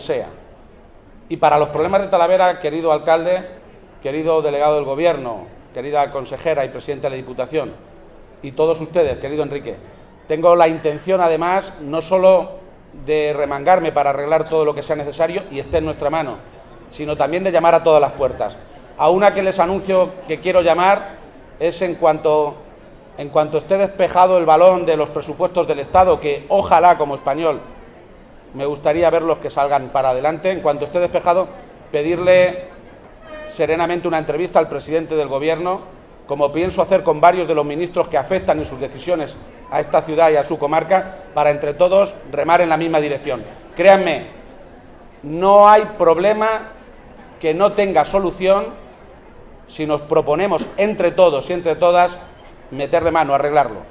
Presidente García-Page: Cena Hermandad San Isidro
corte_presidente_cena_san_isidro.mp3